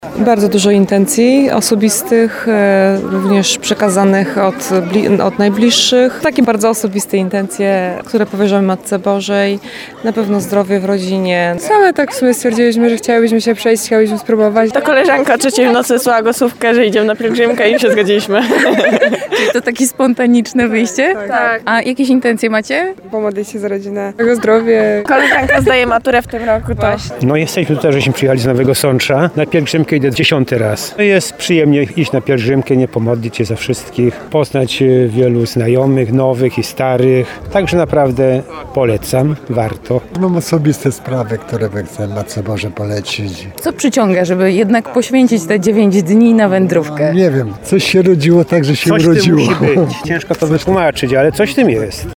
sonda_kzw.mp3